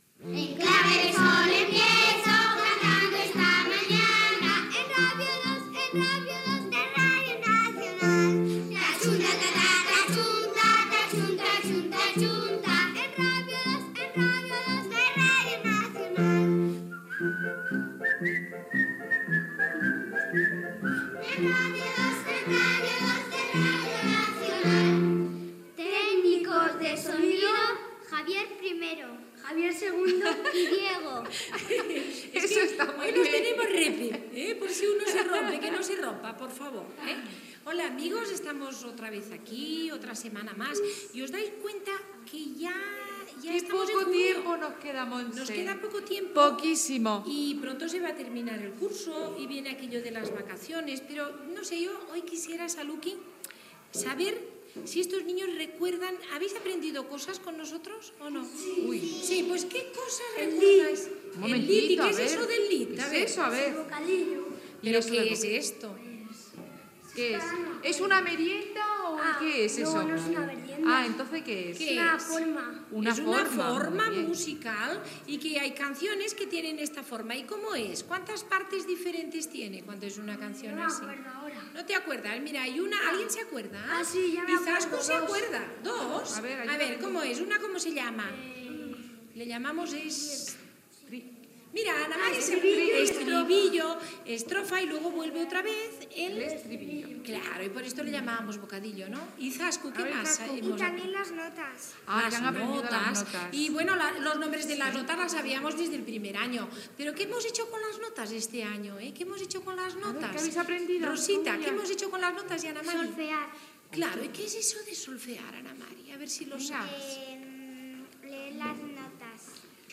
Sintonia cantada del programa, presentació, coses que els nens han après durant la temporada i repàs a terminologia musical
Infantil-juvenil